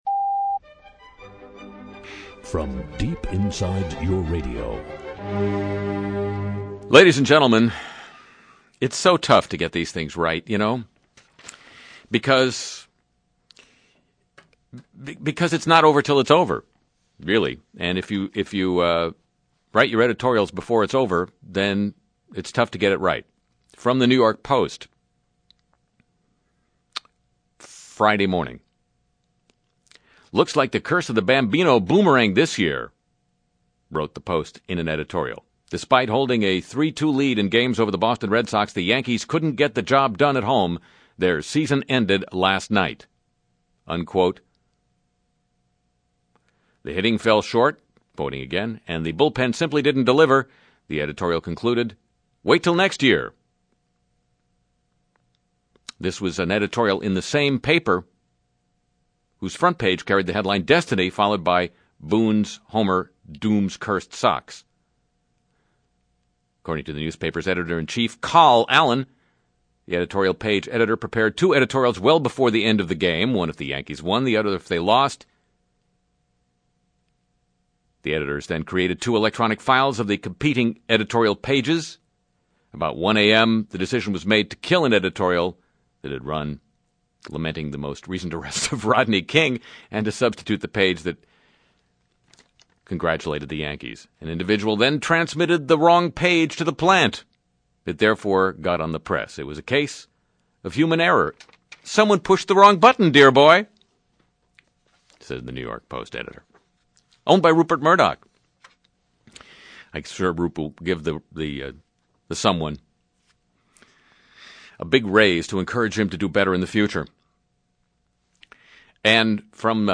Interview with Satan, responding to General Boykin